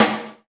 PSNARE 2.wav